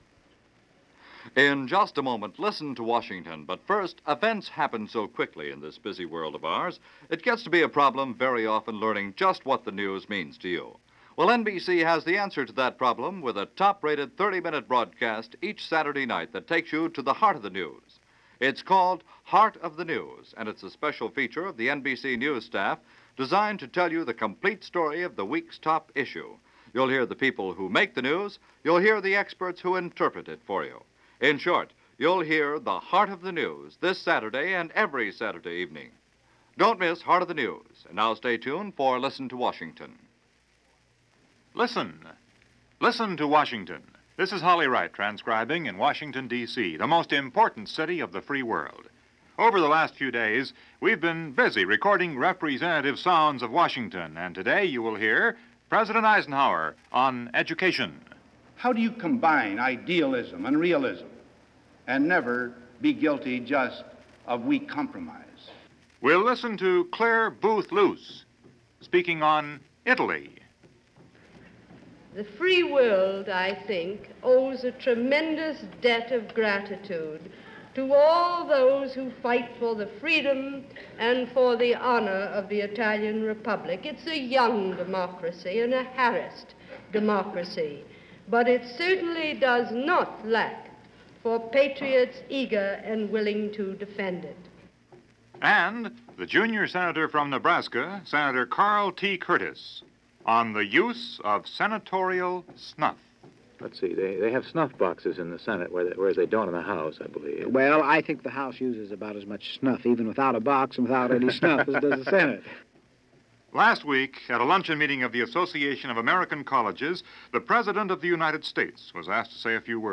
Italy was the focus of attention in an address by Ambassador to Italy, Claire Booth Luce , who addressed a meeting of the Washington Press Club.